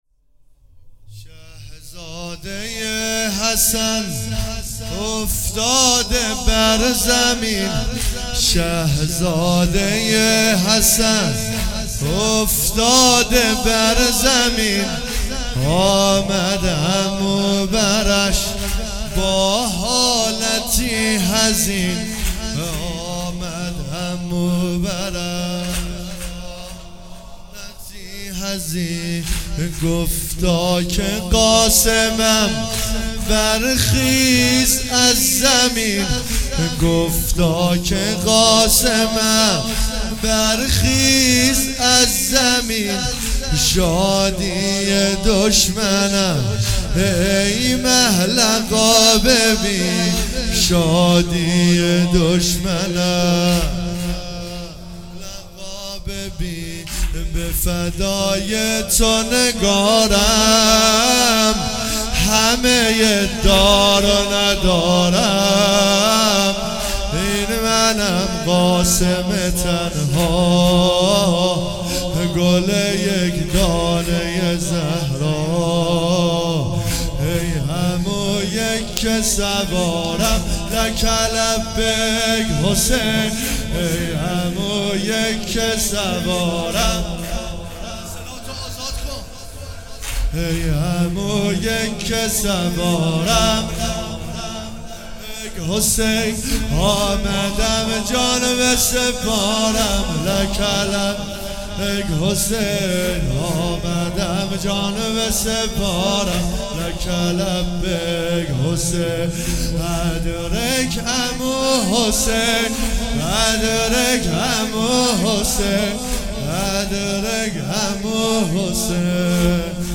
هیئت ام المصائب سلام الله علیها
محرم
مداحی